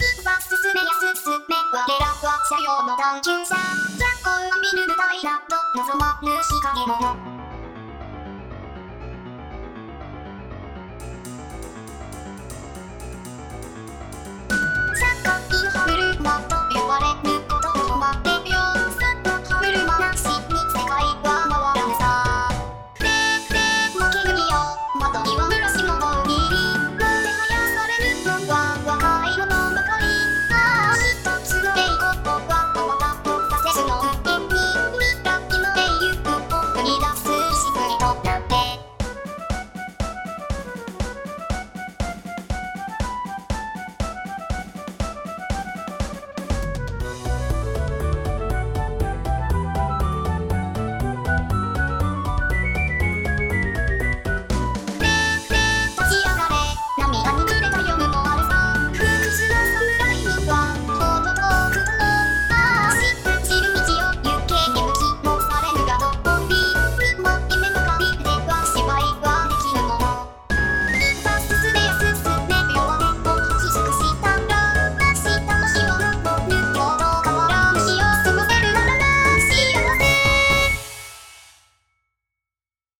UTAU